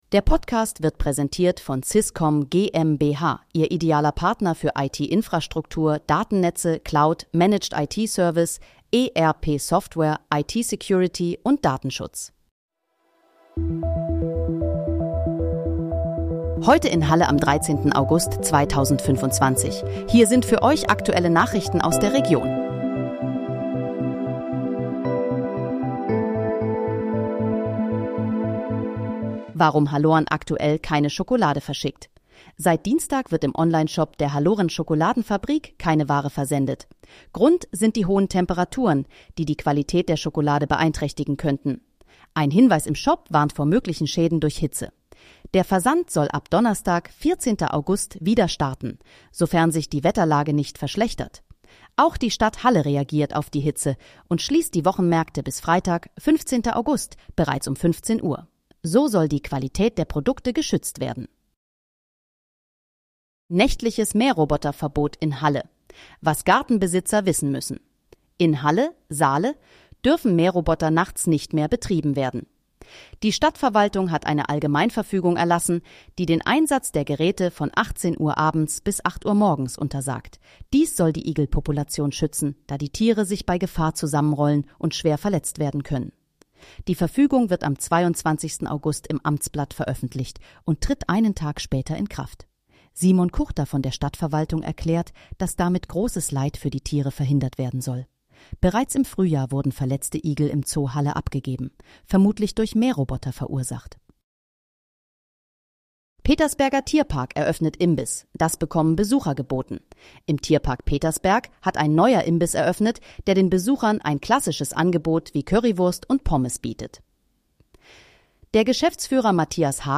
Heute in, Halle: Aktuelle Nachrichten vom 13.08.2025, erstellt mit KI-Unterstützung